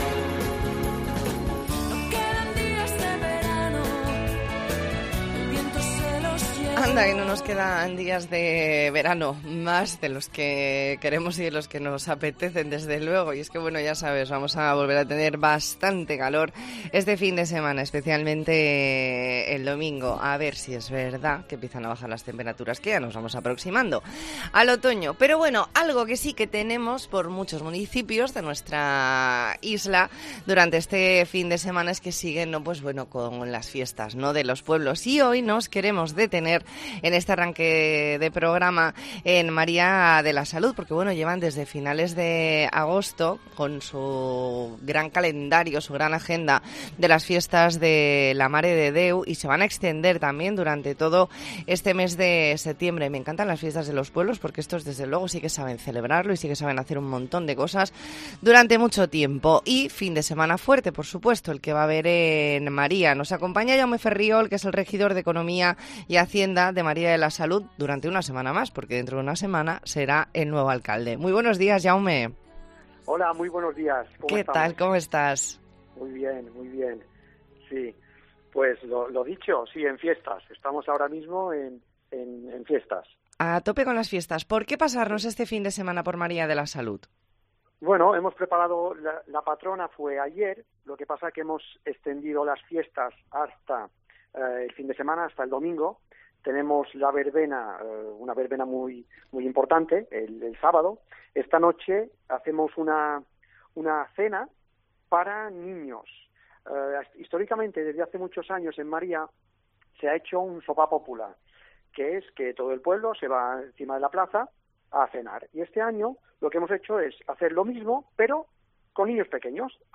Redacción digital Madrid - Publicado el 09 sep 2022, 12:30 - Actualizado 18 mar 2023, 20:35 1 min lectura Descargar Facebook Twitter Whatsapp Telegram Enviar por email Copiar enlace Hablamos con Jaume Ferriol, regidor de Economía y Hacienda de Maria de la Salut .